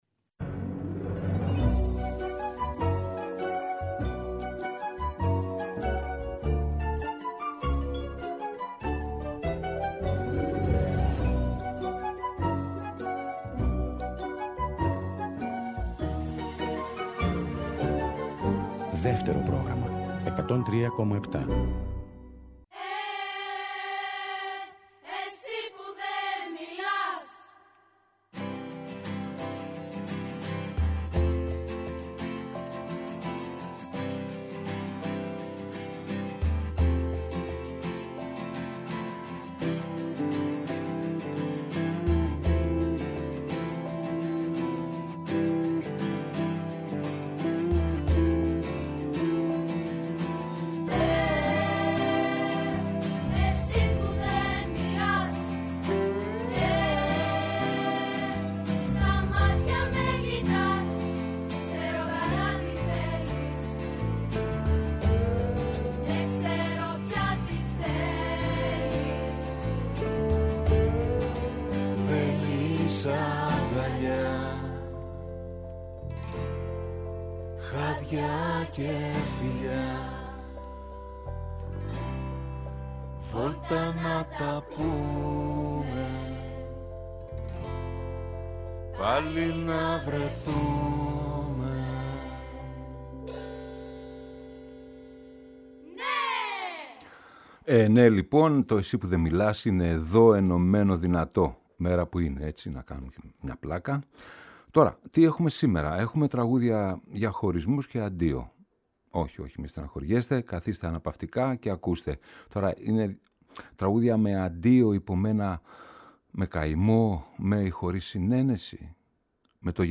Τραγούδια για χωρισμούς και αντίο θα έχει το ”Εσύ που δε μιλάς”